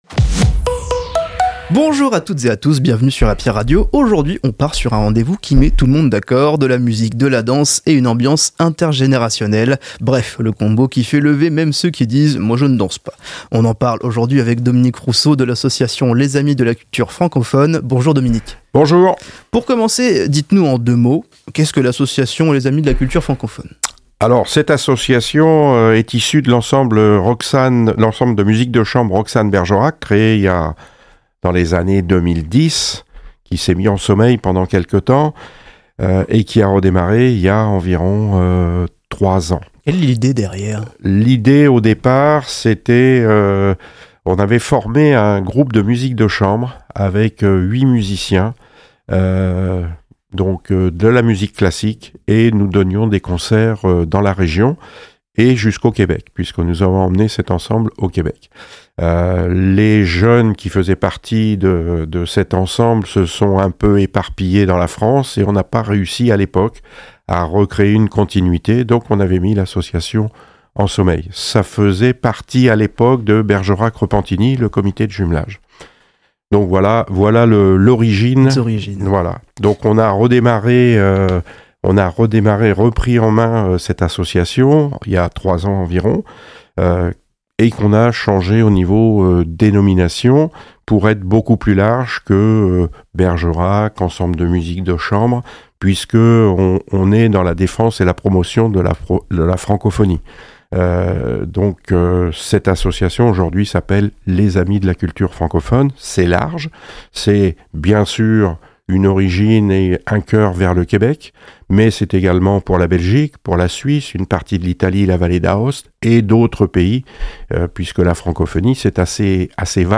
Les interviews Happy Radio